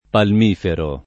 palmifero [ palm & fero ]